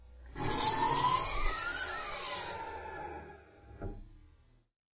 车辆的声音效果 " 汽车门的砰砰声，打开和关上
描述：打开和关闭欧宝Astra 1.6_16V的门。
Tag: 汽车 ASTRA 关闭力 汽车 欧宝 窗口 失控 速度快 变焦 汽车 H2 汽车